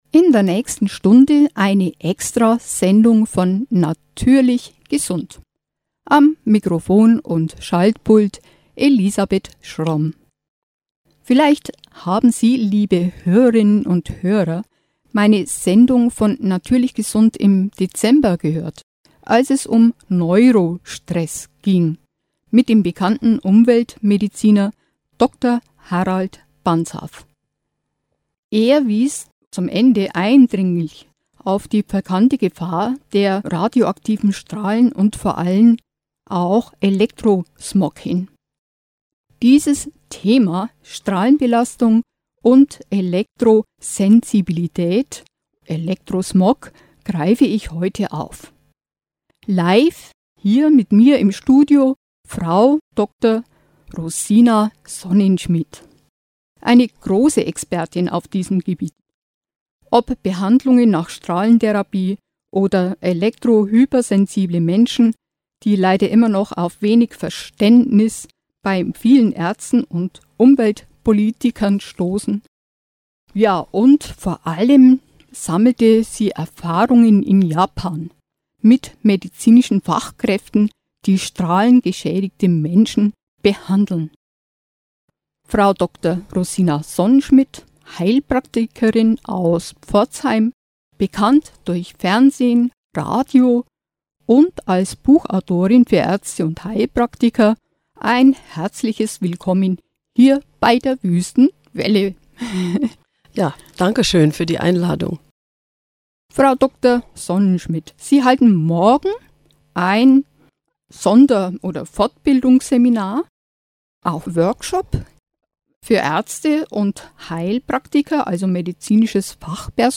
erklärt im Interview